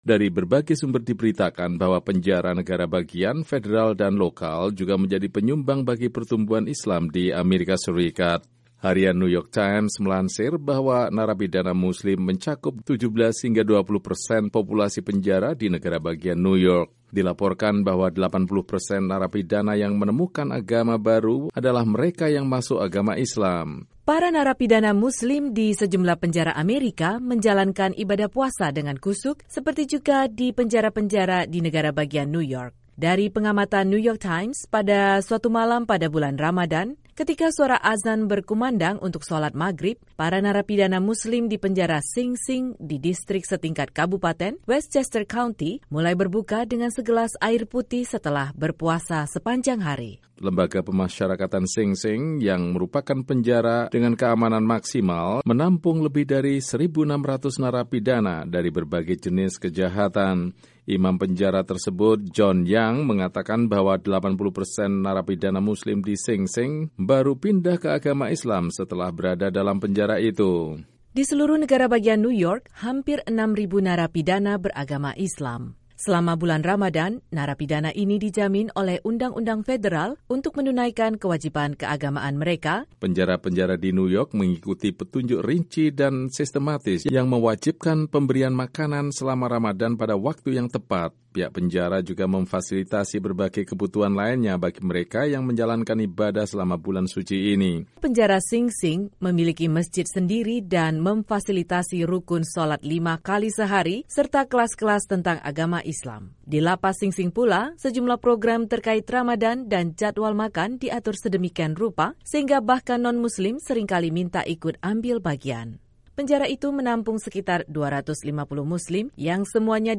Tidak asing lagi bahwa penjara lokal, negara bagian maupun federal telah memberikan kontribusi pada kenaikan jumlah pemeluk Agama Islam di Amerika. Berikut laporan tentang bagaimana para mualaf itu menjalankan ibadah puasa selama Ramadhan.